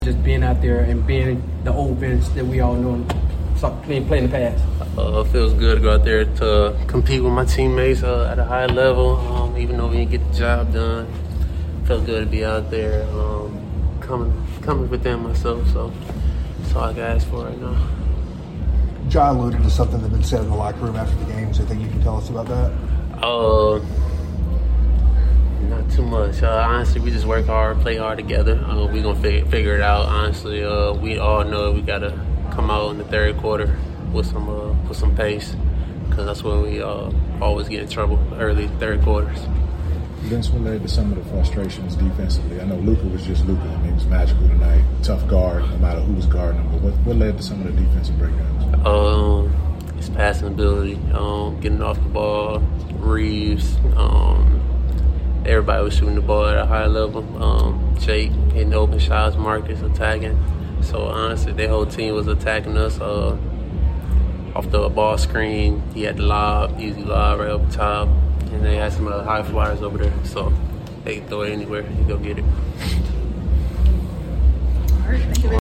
Memphis Grizzlies Guard Vince Williams Jr. Postgame Interview after losing to the Los Angeles Lakers at FedExForum.